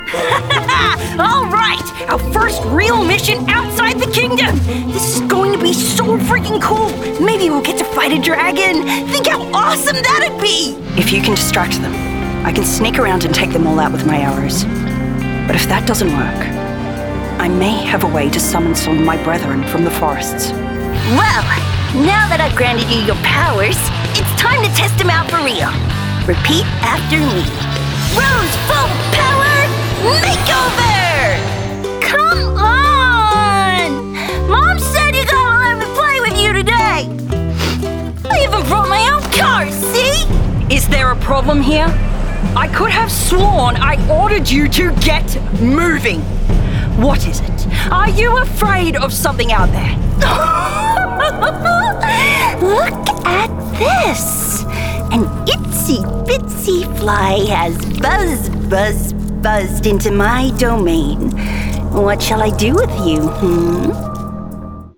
Demo
Teenager, Child, Young Adult
australian
standard us
character - child
character - teenager